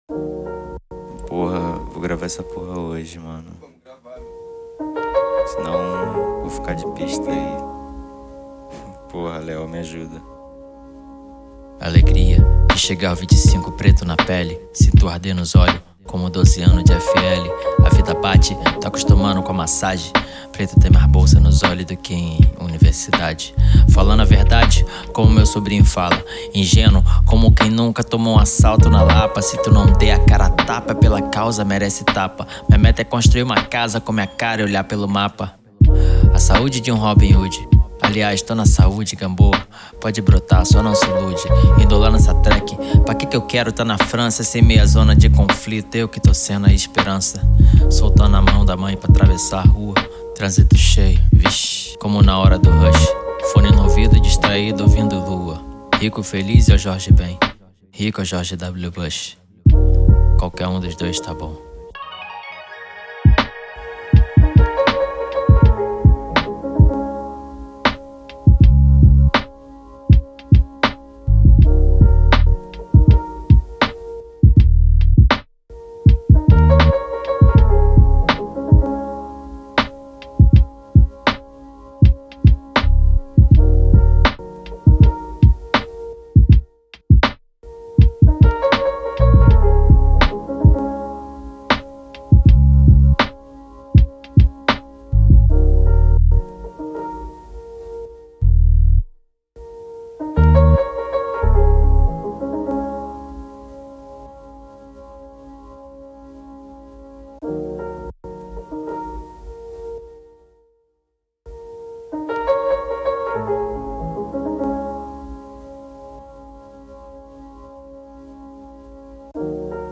EstiloHip Hop / Rap